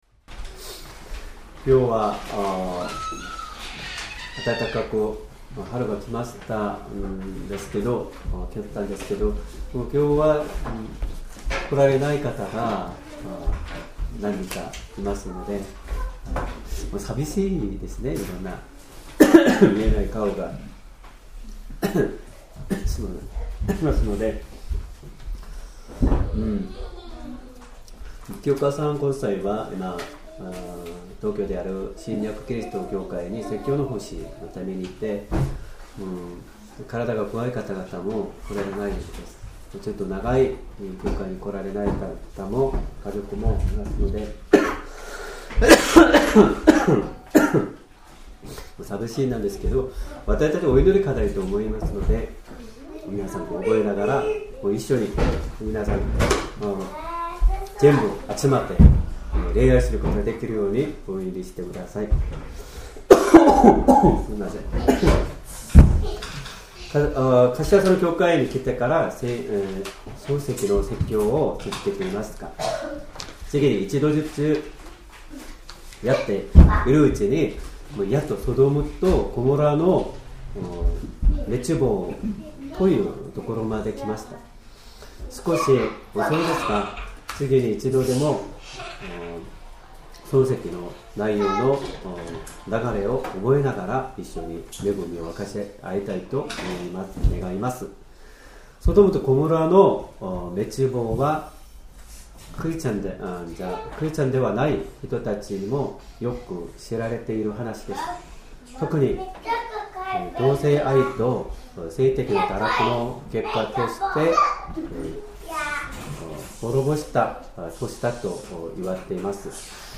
Sermon
2025年3月23日 主日礼拝 説教 「さばきの日に救い出されたロト」 聖書 創世記 19章 1～38節 19:1 その二人の御使いは、夕暮れにソドムに着いた。